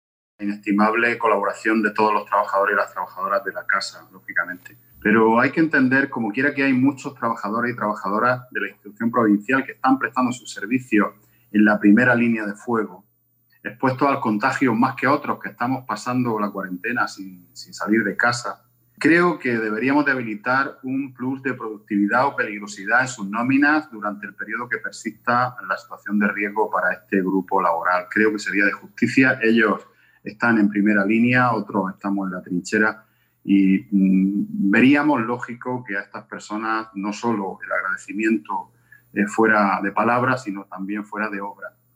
Juan Antonio Lorenzo, portavoz del Grupo Socialista en la Diputación Provincial